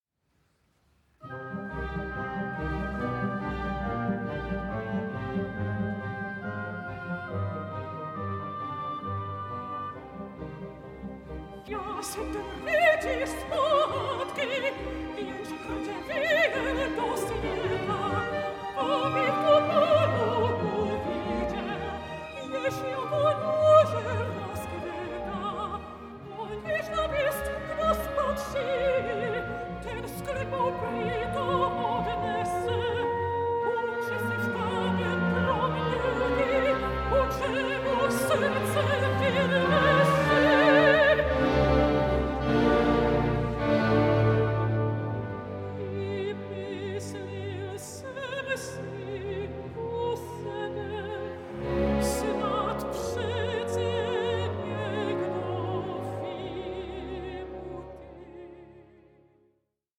mezzo-soprano